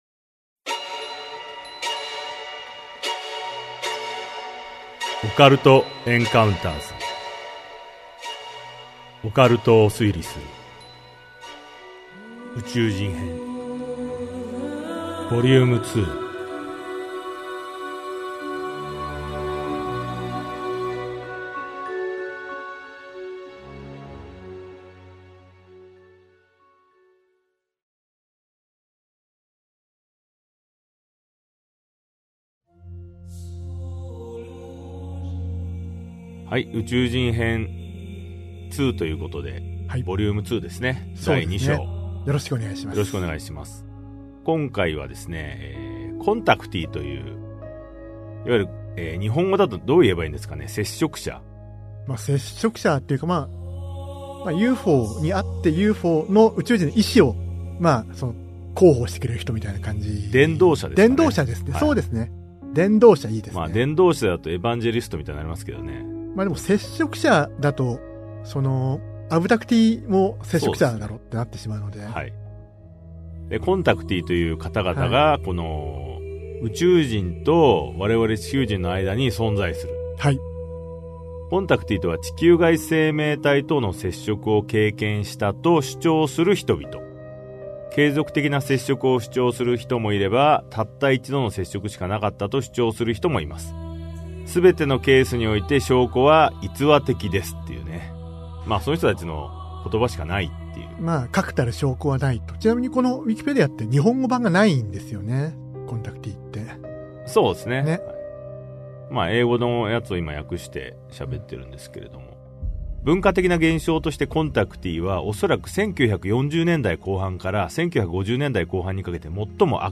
[オーディオブック] オカルト・エンカウンターズ オカルトを推理する Vol.05 宇宙人編2